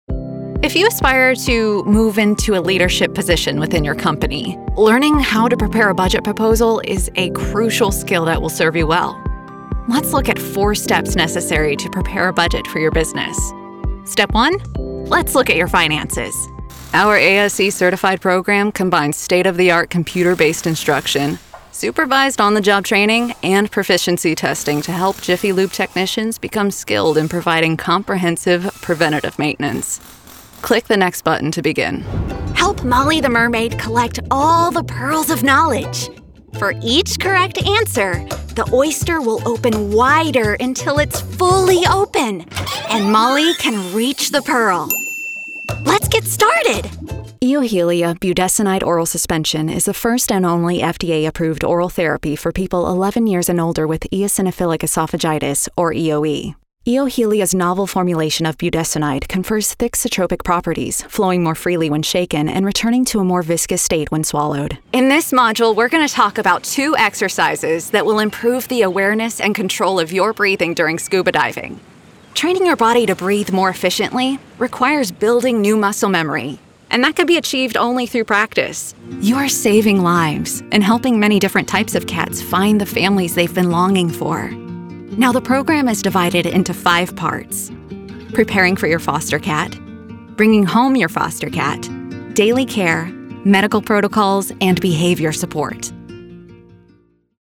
Warm, emotional, personal
eLearning